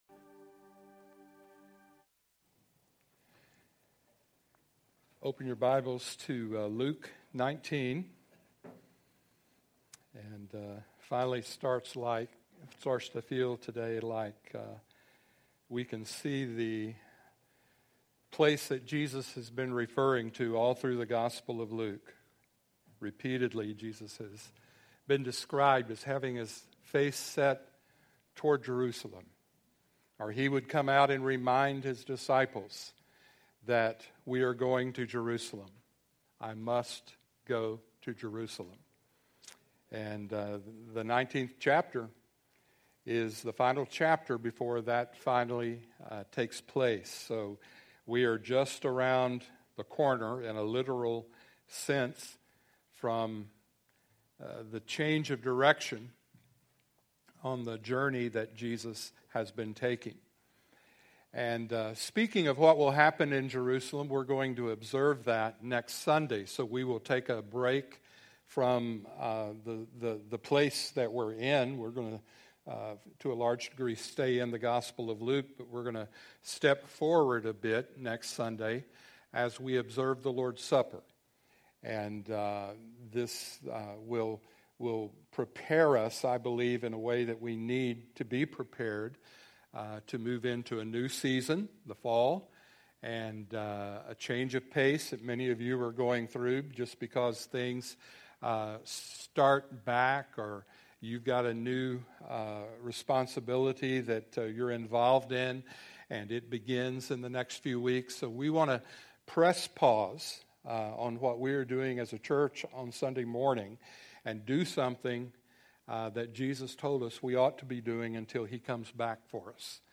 Sermons | Crossroads Church